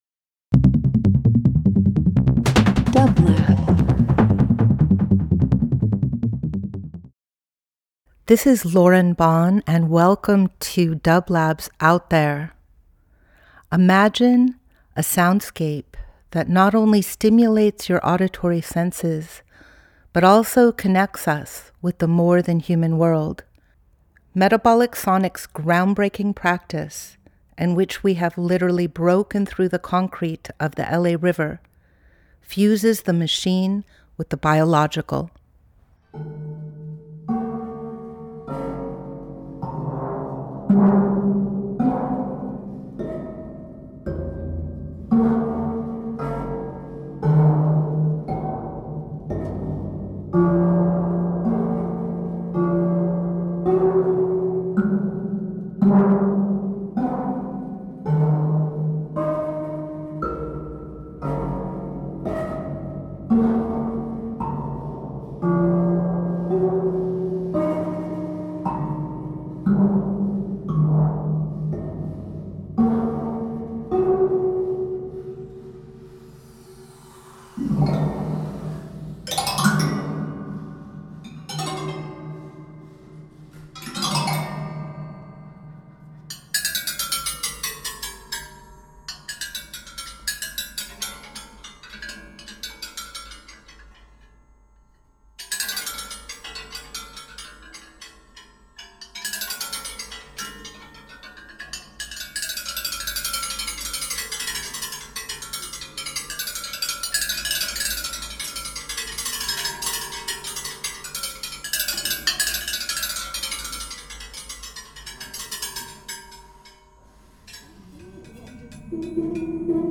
Each week we present field recordings that will transport you through the power of sound.
Out There ~ a field recording program
Metabolic Sonics specializes in exploring the captivating sound waves and vibrations of the web of life.
Each week we will bring field recordings of Metabolic’s current project Bending the River, and archival material from past ventures. Bending the River is an adaptive reuse of the LA River infrastructure that reimagines the relationship between Los Angeles and the river that brought it into existence.
Metabolic Sonics Metabolic Studio Out There ~ a field recording program 10.30.25 Ambient Experimental Sound Art Voyage with dublab into new worlds.